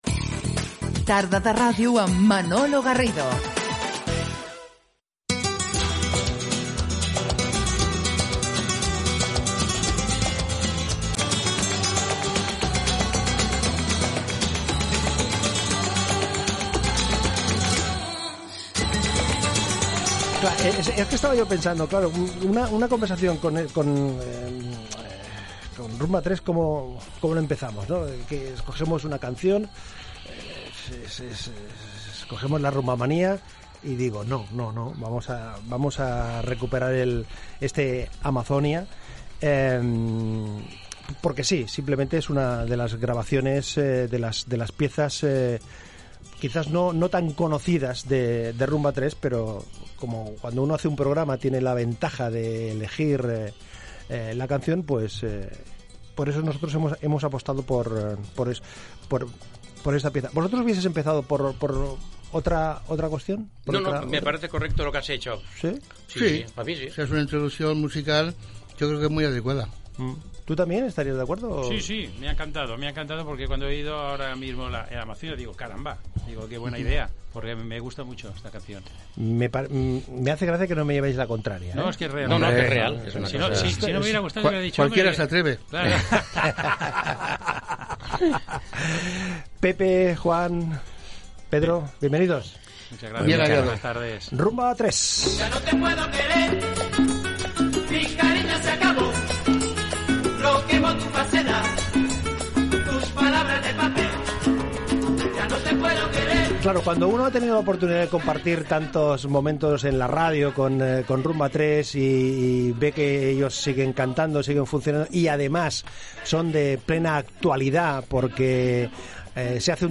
Toquen un parell de temes en directe
Entreteniment